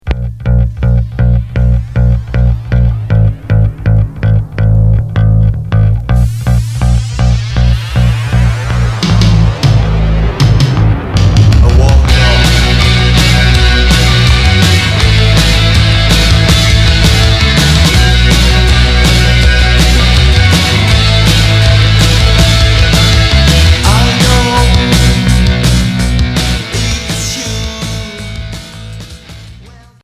Cold punk Unique Maxi 45t retour à l'accueil